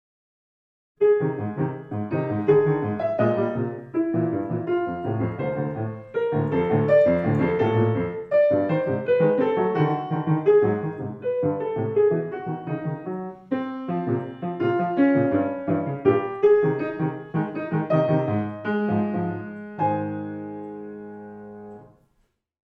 flott